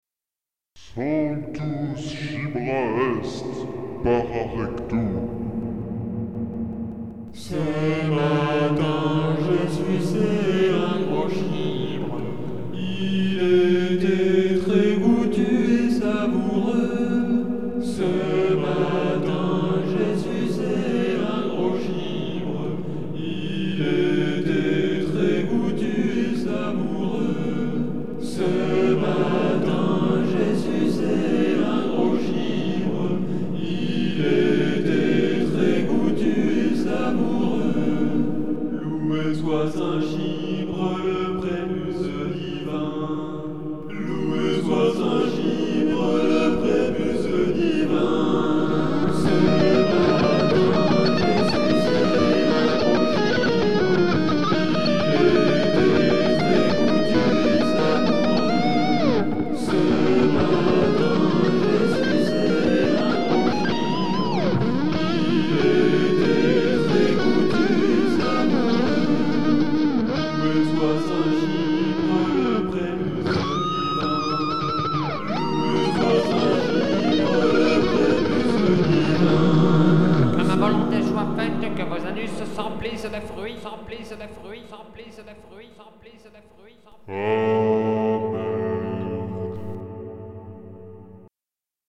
vocalise chibrales.mp3